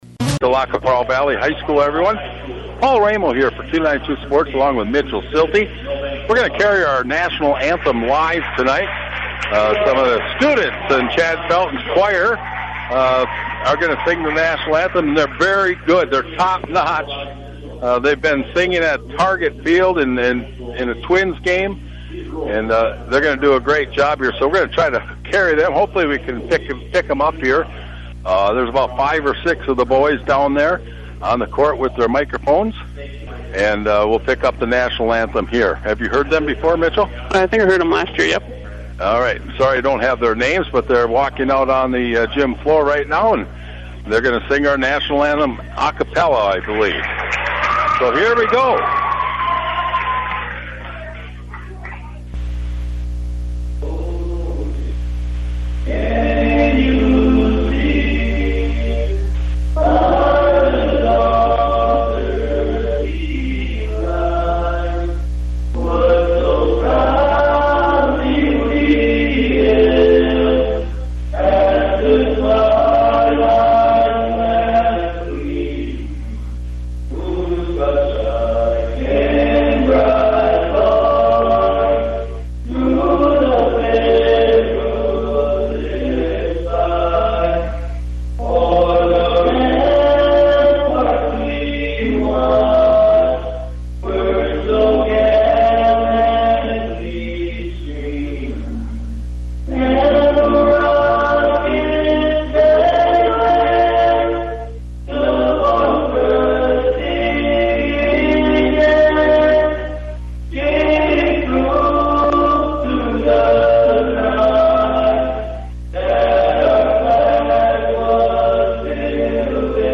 National Anthem by LQPV students before the boys basketball game with Milbank